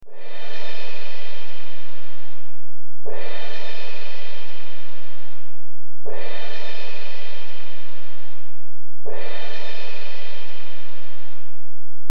Sound recording: Orchestral Cymbals 2
Orchestral cymbal hit (WAV file)
Relevant for: percussion,, orchestra.
Try preview above (pink tone added for copyright).